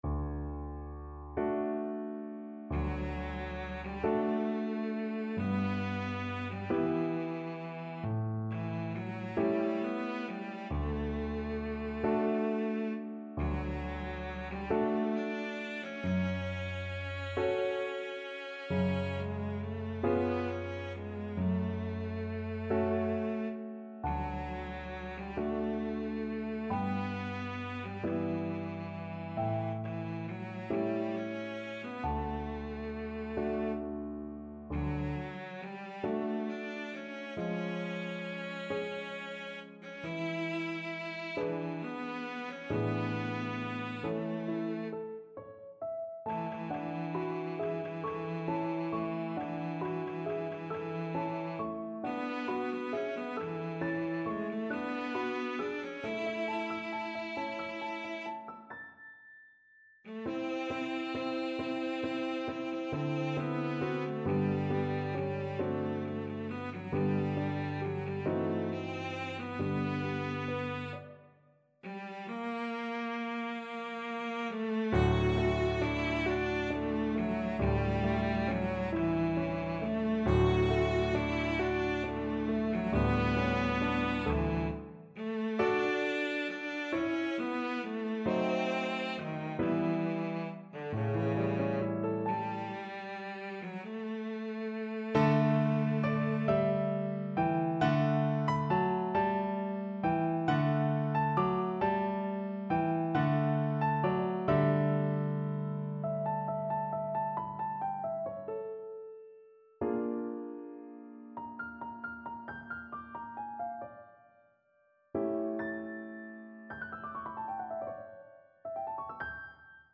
Cello version
6/8 (View more 6/8 Music)
Andante .=45
Classical (View more Classical Cello Music)